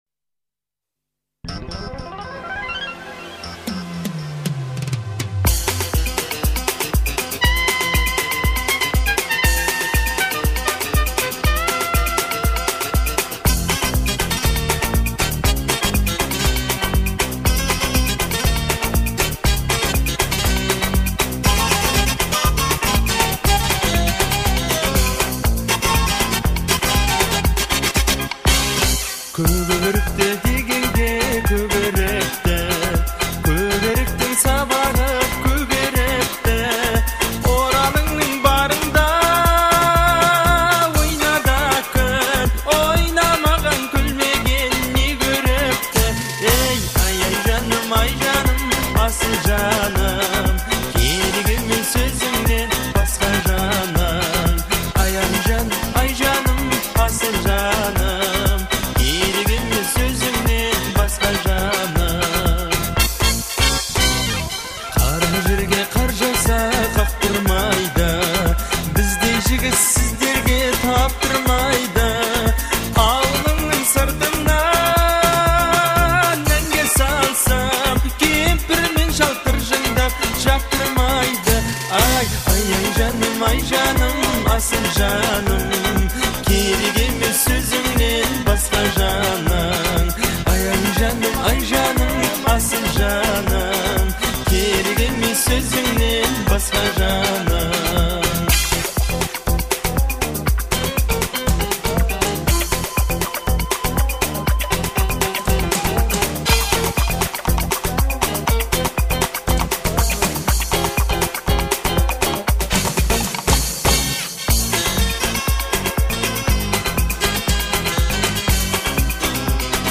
это яркая и мелодичная песня